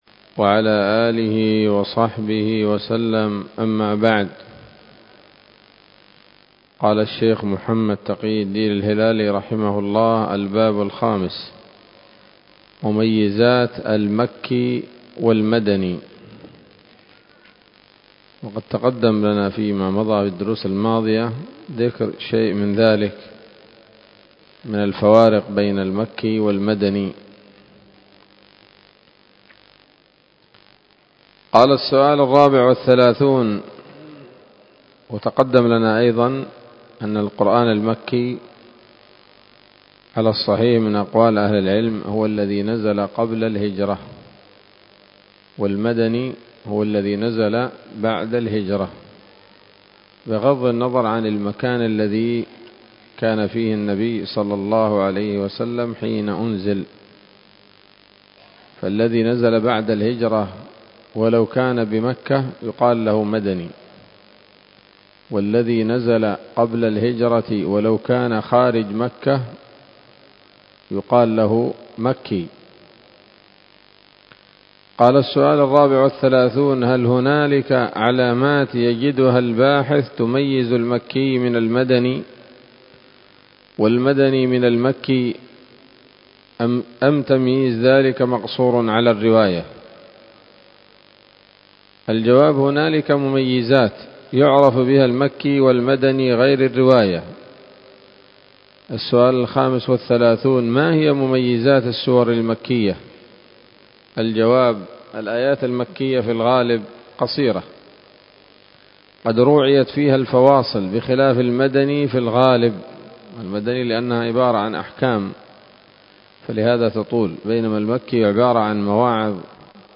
الدرس العاشر من كتاب نبذة من علوم القرآن لـ محمد تقي الدين الهلالي رحمه الله